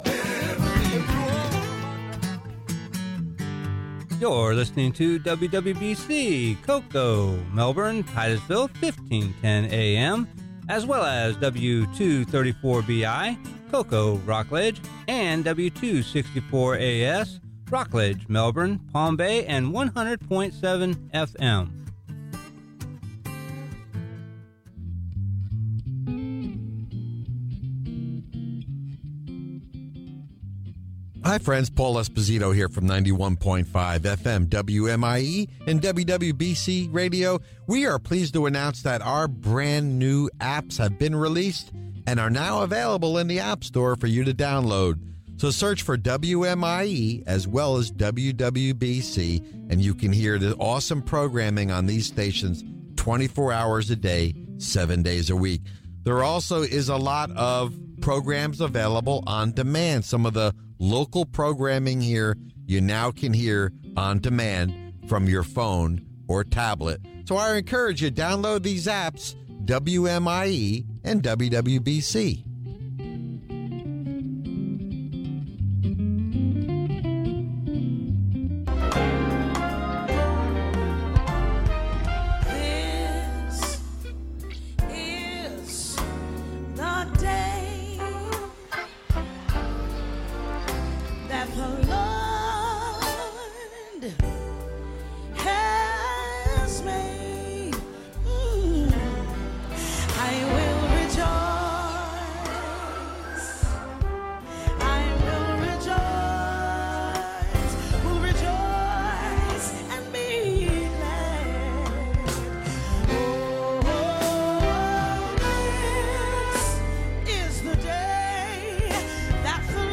Sermon: "Justified By Faith" Romans Ch 5 Part 1